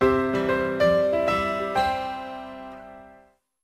알림음 8_melody2.ogg